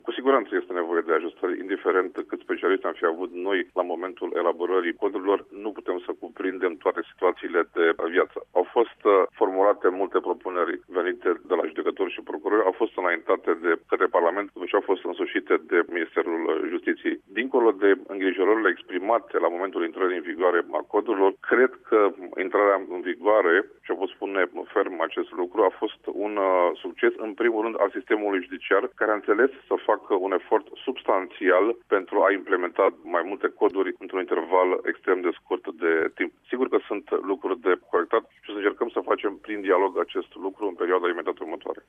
Invitat în această dimineaţă la Radio România Actualităţi, el a admis că, unele există unele prevederi în actuala legislaţie care trebuie ajustate: